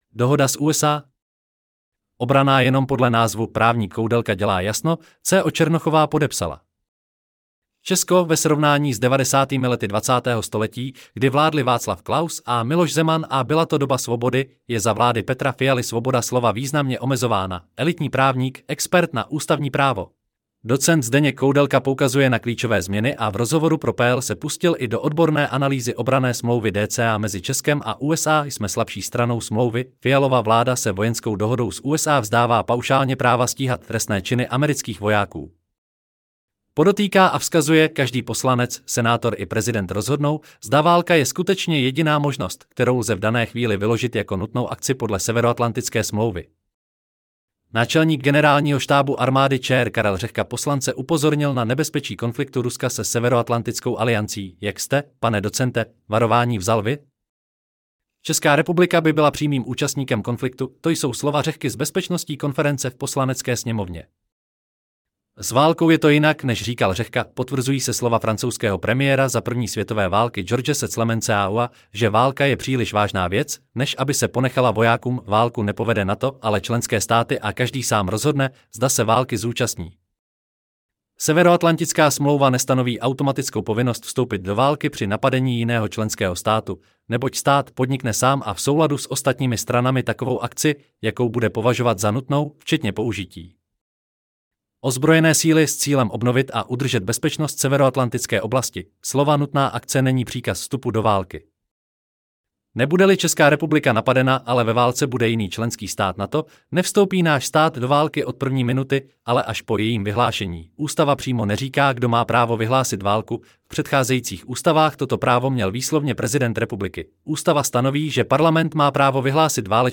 co Černochová podepsala Celý článek si můžete poslechnout v audio verzi ZDE: DOHODA S USA?